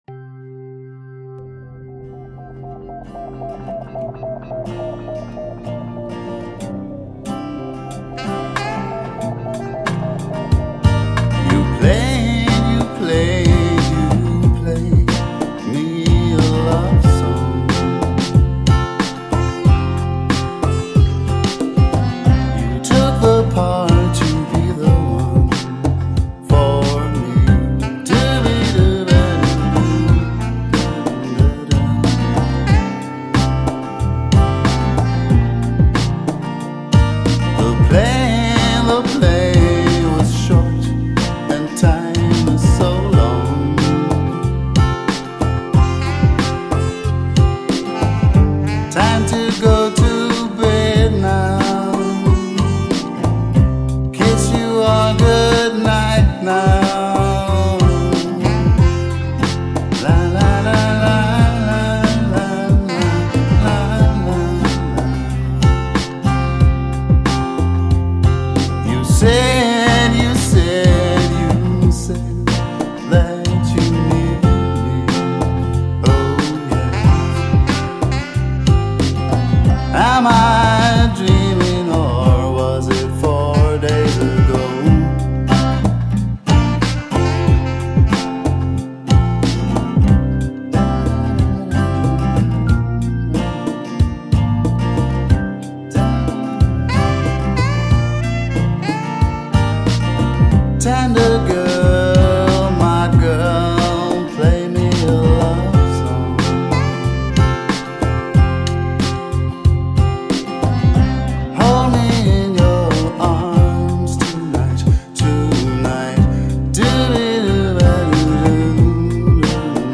Ballade, d + b + g + sax + voc + sitar + organ
Saxophone